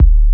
50BASS01  -L.wav